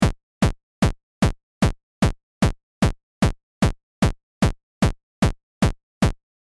バスドラム その1 （矩形波）
これはアタックが強めで、パンチのある音色です。
使用する波形はSquare（矩形波）